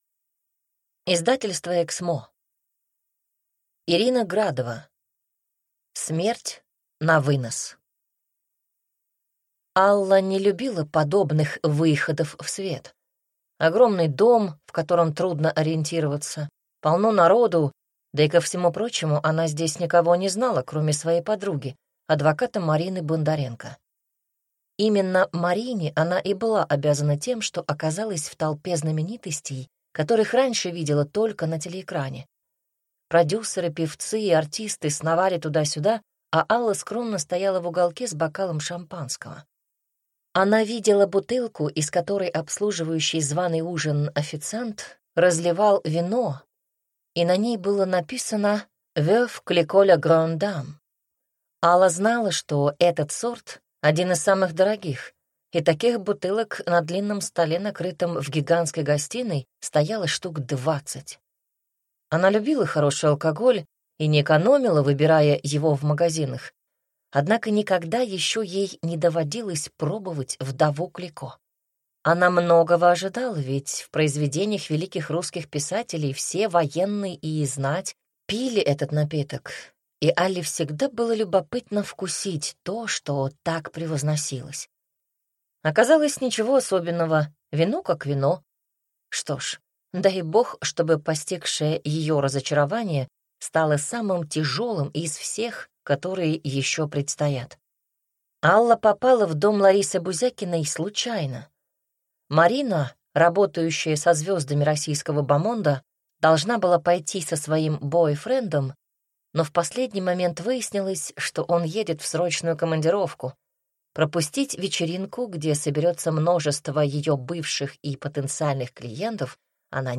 Как сбежать от дракона и открыть трактир (слушать аудиокнигу бесплатно